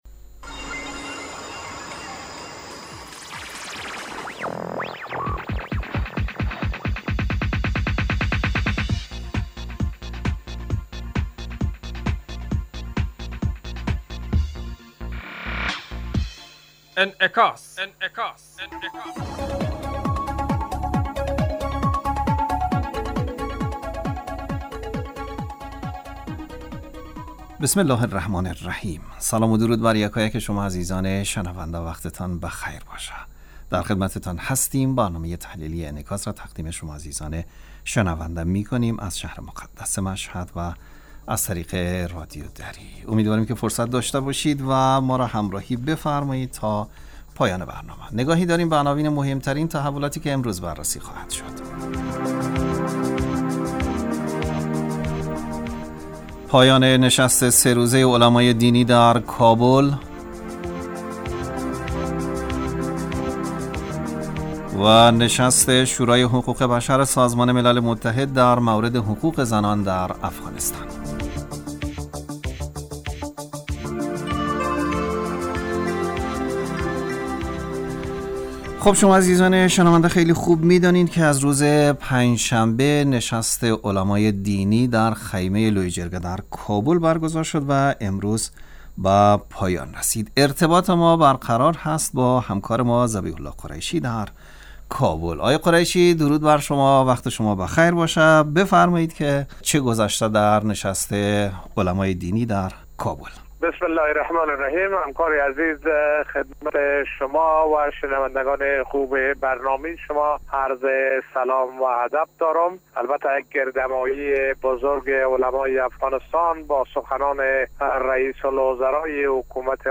برنامه انعکاس به مدت 35 دقیقه هر روز در ساعت 18:55 بعد ظهر بصورت زنده پخش می شود. این برنامه به انعکاس رویدادهای سیاسی، فرهنگی، اقتصادی و اجتماعی مربوط به افغانستان و تحلیل این رویدادها می پردازد.